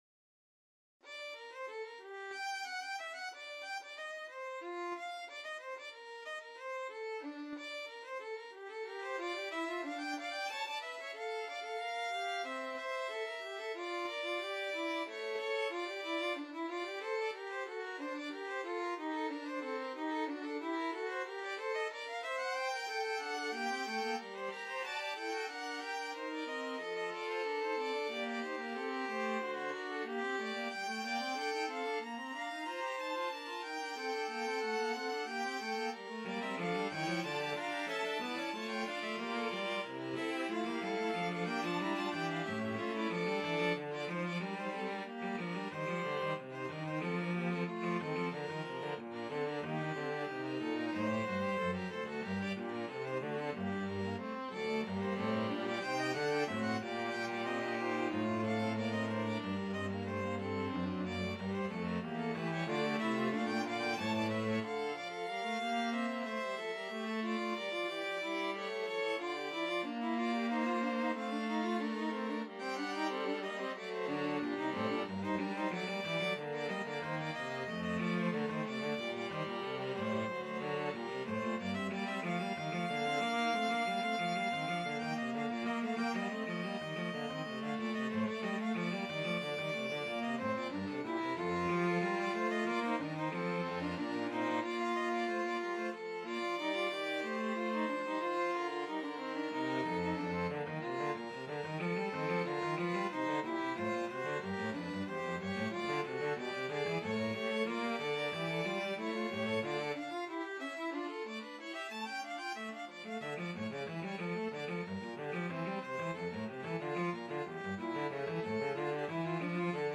Violin 1Violin 2ViolaCello
4/4 (View more 4/4 Music)
Classical (View more Classical String Quartet Music)